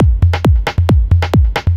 DS 135-BPM A6.wav